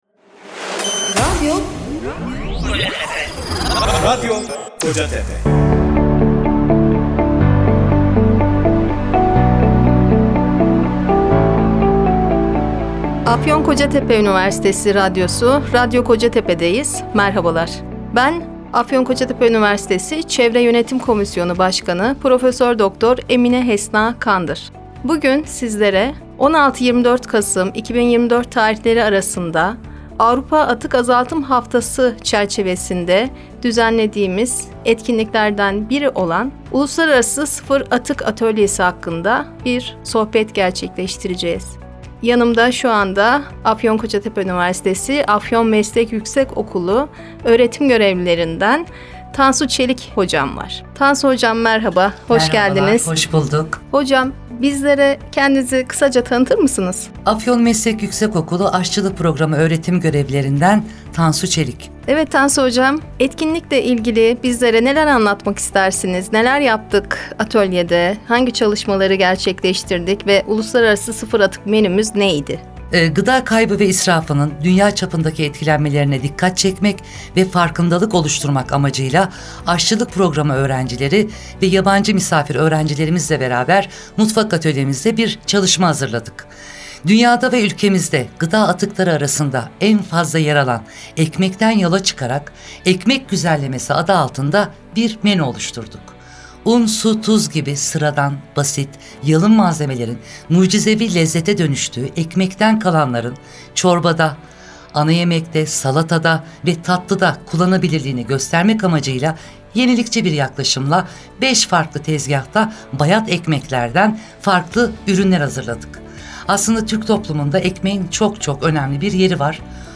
1. “Sıfır Atık Mutfak” Söyleşi Programı Radyo Kocatepe’de Yayımlandı